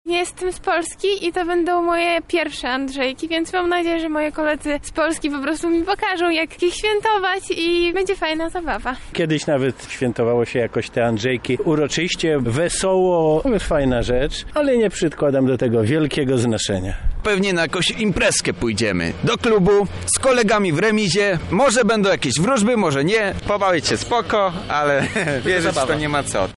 Zapytaliśmy mieszkańców Lublina w jaki sposób oni spędzą to święto.